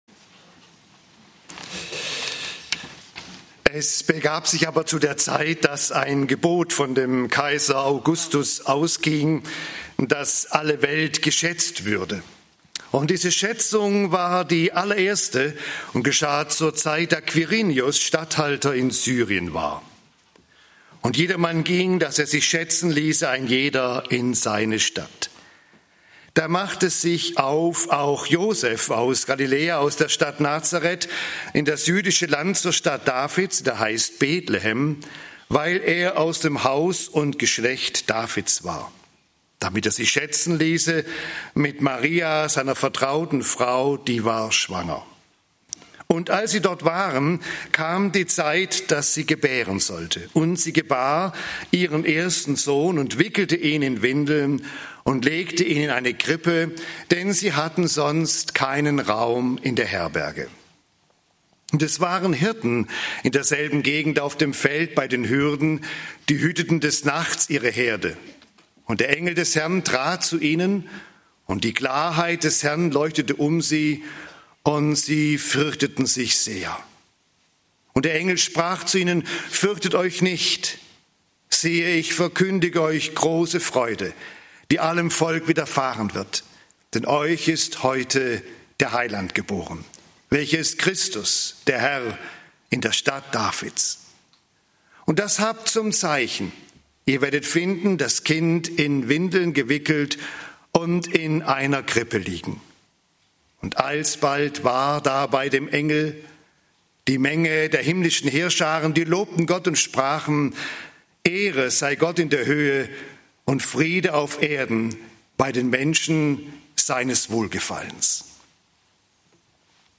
(Hes. 37, 24-28) - Gottesdienst Christvesper ~ LaHö Gottesdienste Podcast